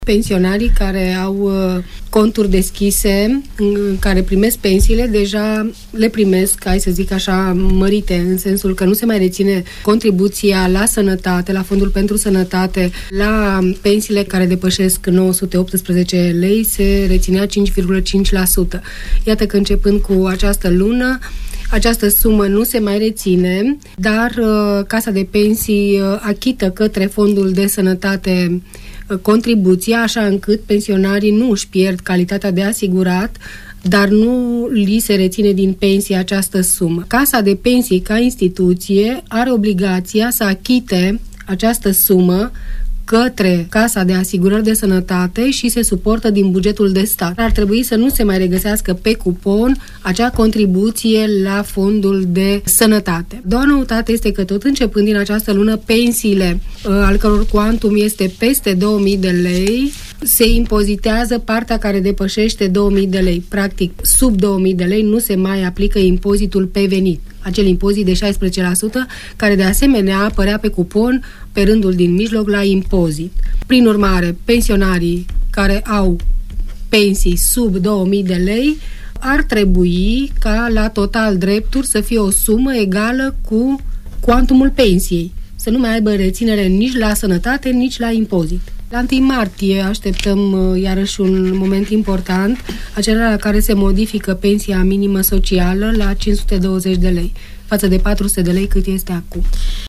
a explicat azi în emisiunea ”Părerea ta” că prin acest lucru nu se va pierde calitatea de asigurat: